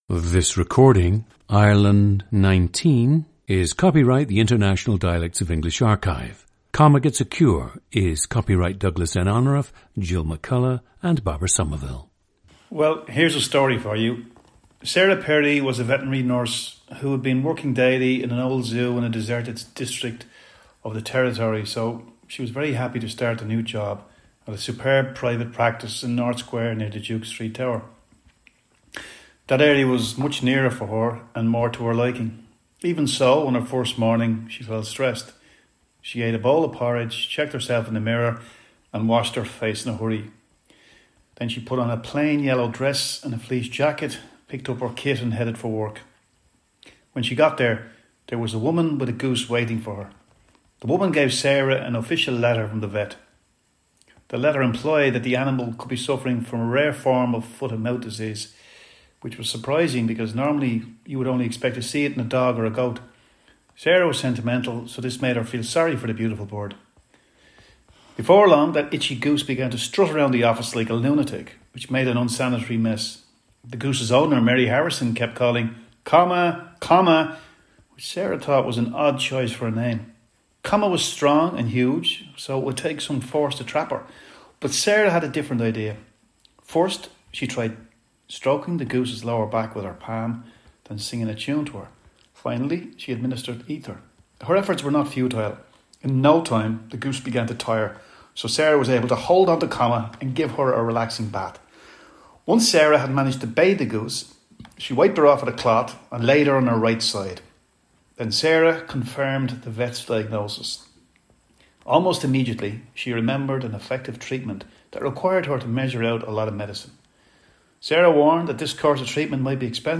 PLACE OF BIRTH: Dublin, Ireland (north side)
GENDER: male
Other than that, he has lived his entire life on the north side of Dublin.
• Recordings of accent/dialect speakers from the region you select.
The recordings average four minutes in length and feature both the reading of one of two standard passages, and some unscripted speech.